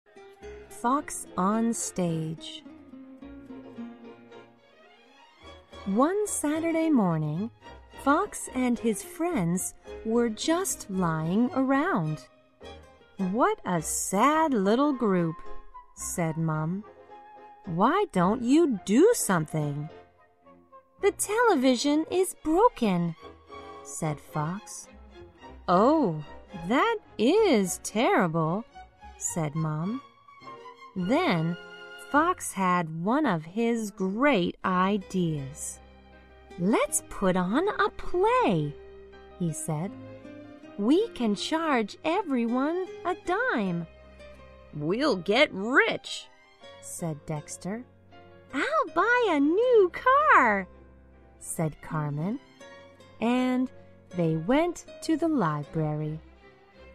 在线英语听力室小狐外传 第70期:小狐演戏的听力文件下载,《小狐外传》是双语有声读物下面的子栏目，非常适合英语学习爱好者进行细心品读。故事内容讲述了一个小男生在学校、家庭里的各种角色转换以及生活中的趣事。